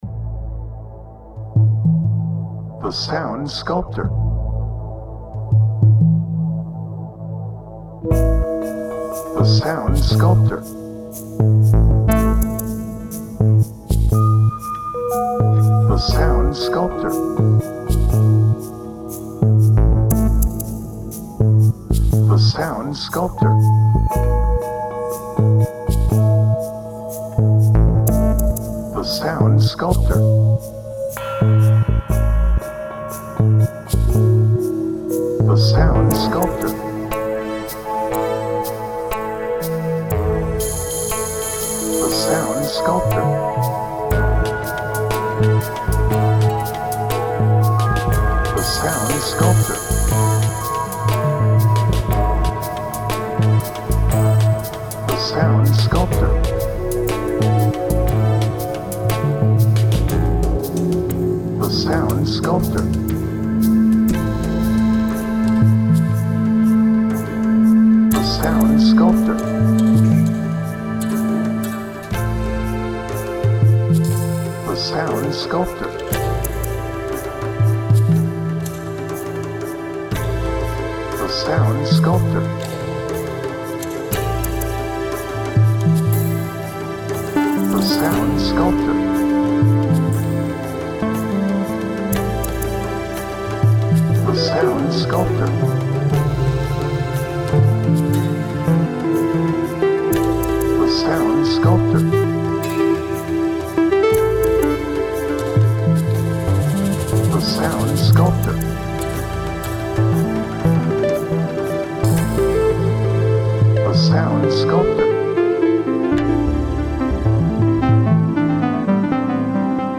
Brooding
Dark
Edgy
Reflective